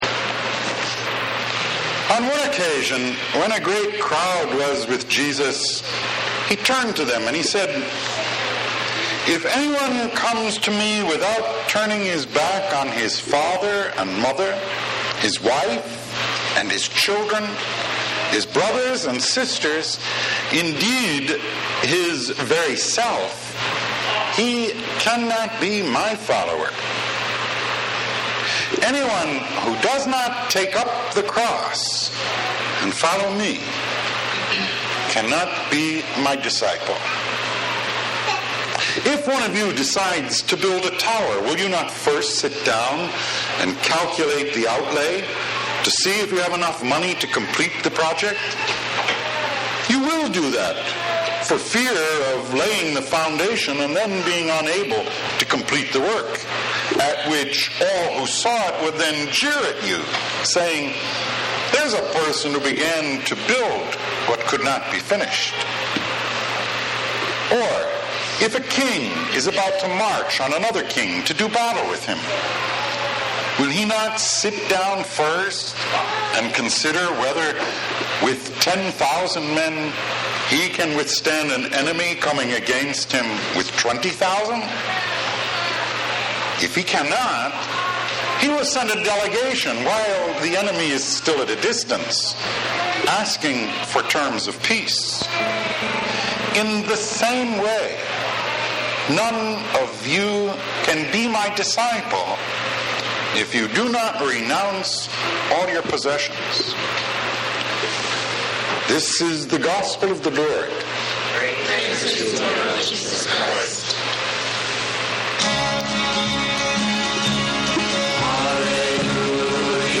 Romero – Weekly Homilies